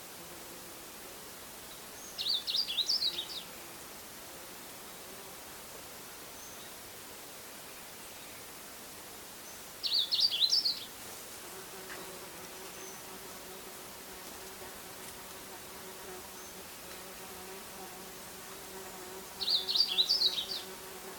Cyanoloxia moesta (Hartlaub, 1853)
Nome em Inglês: Blackish-blue Seedeater
Local: RPPN Corredeiras do Rio Itajai - Itaiópois - SC